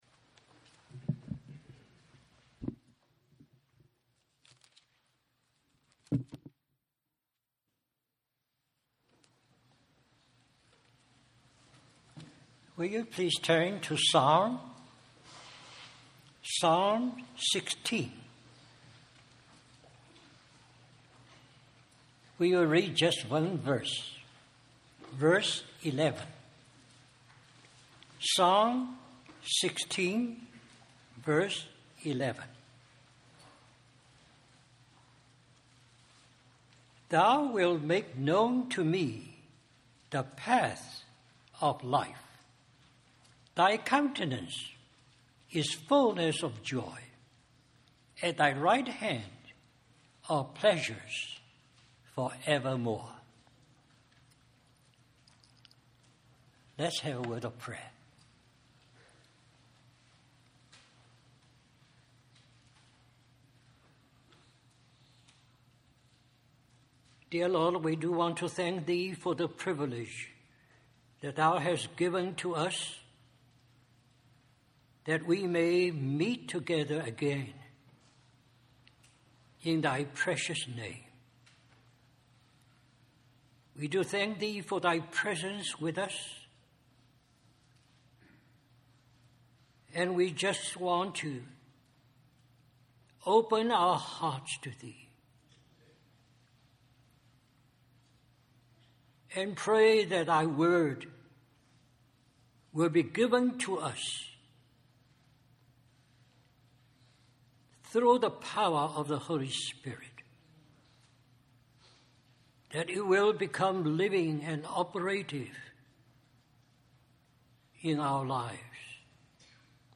Western Christian Conference